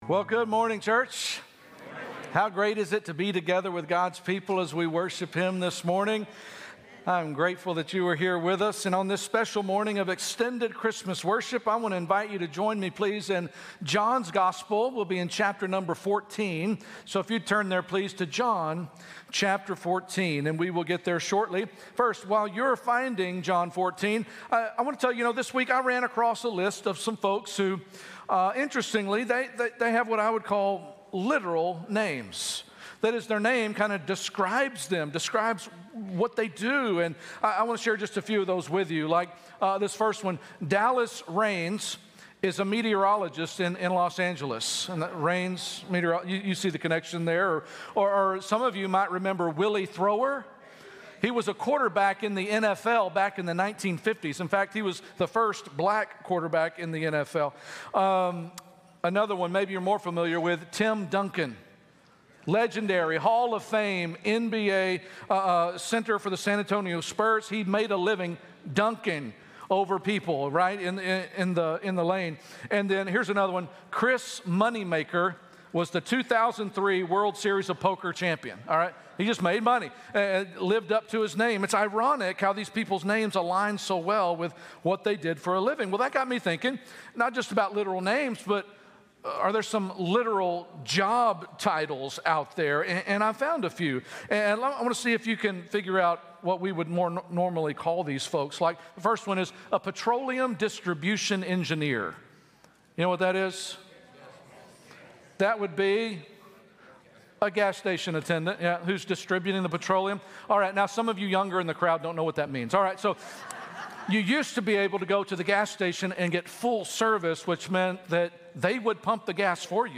Sunday Message 12/15 - God With Us In the Holy Spirit